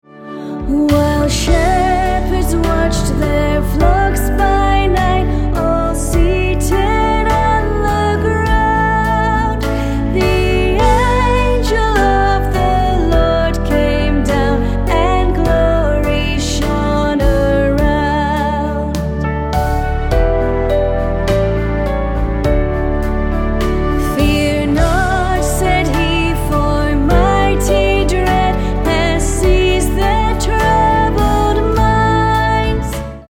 Acoustic